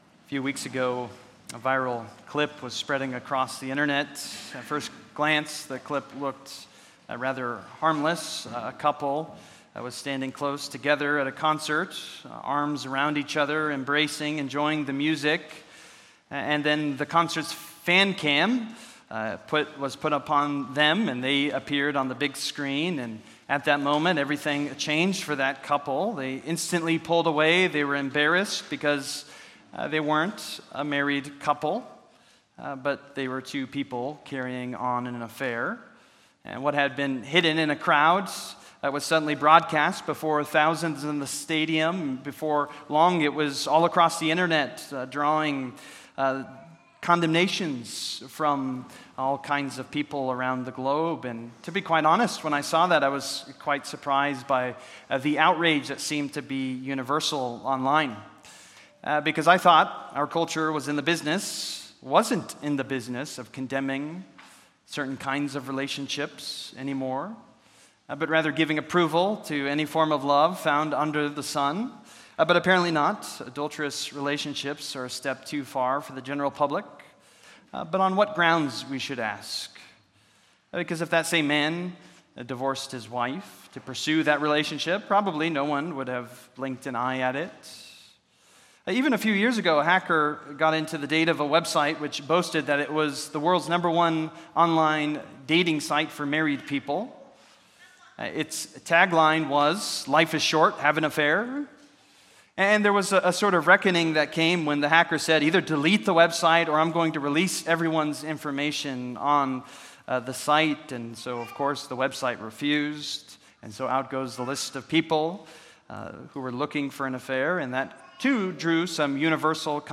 Service: Sunday Morning